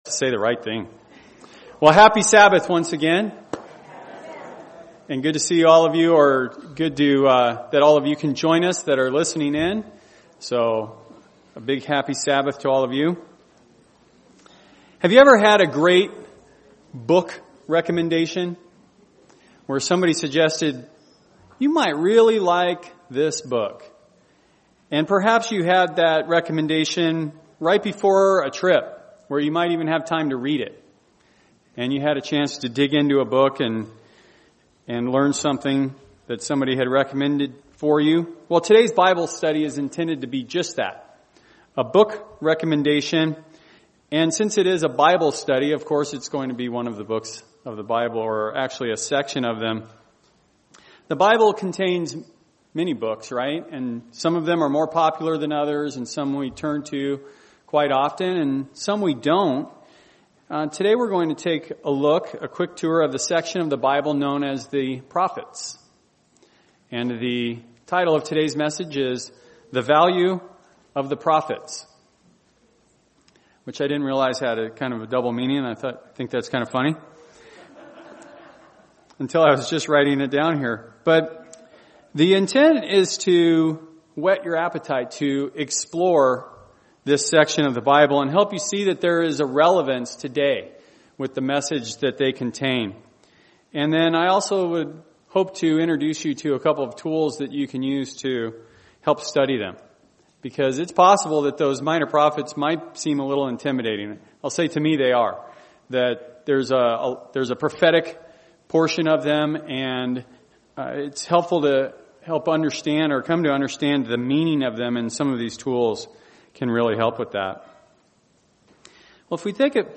A Bible Study: History and Lessons from the Prophets.